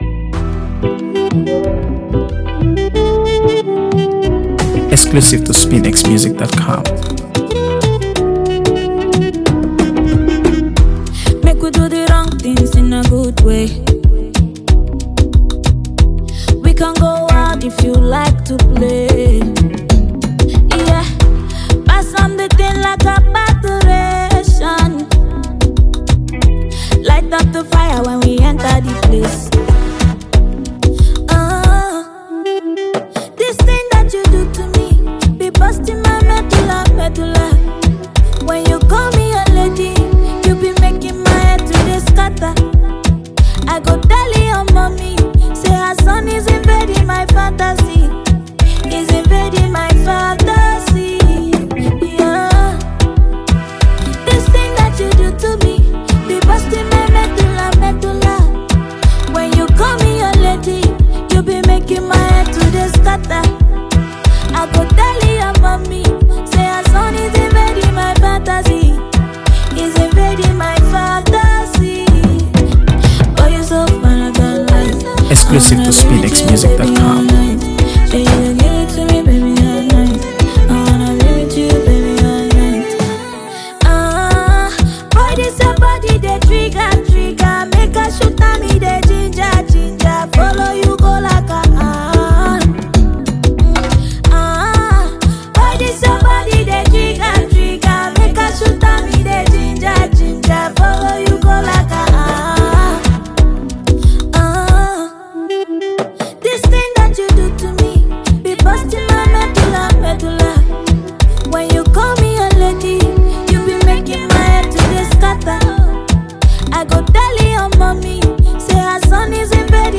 AfroBeats | AfroBeats songs
Super talented Nigerian singer
the track stands out with its smooth production